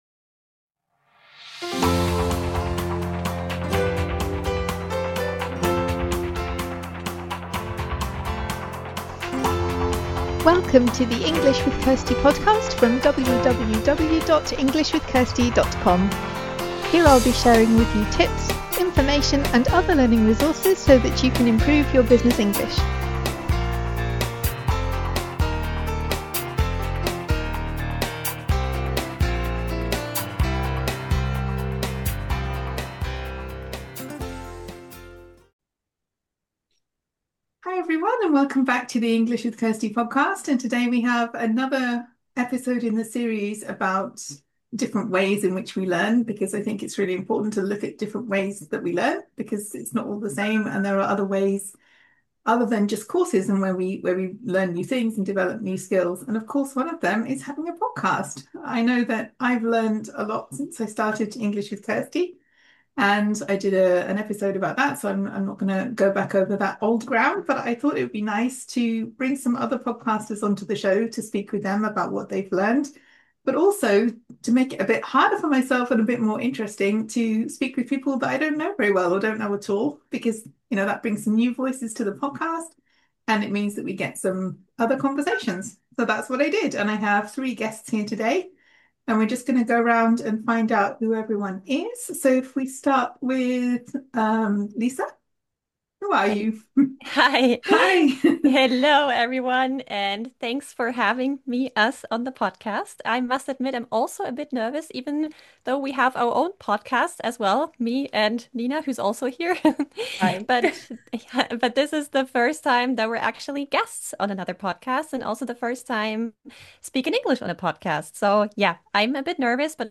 I decided to give myself the challenge of inviting guests whom I didn’t know so that I would meet some new podcasters too.